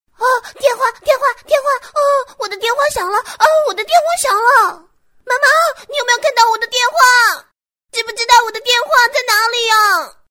女声配音